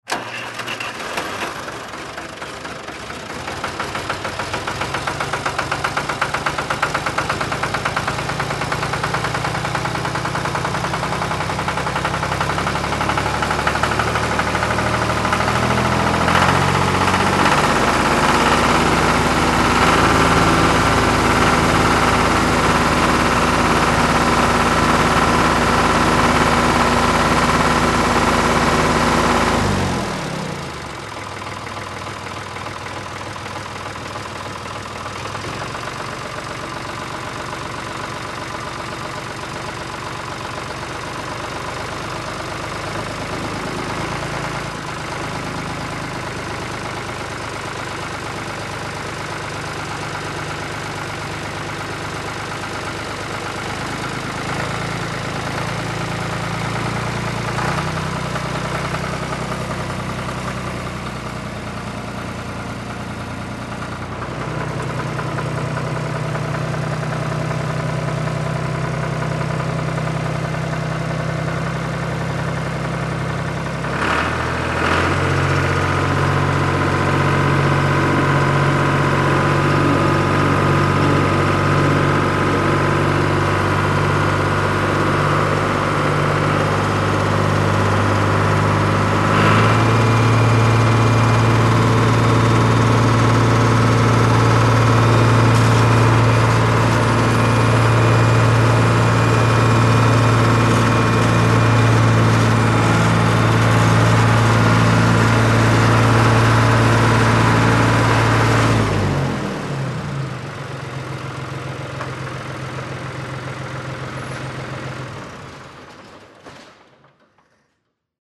Звук тракторного двигателя при разгоне и холостом ходе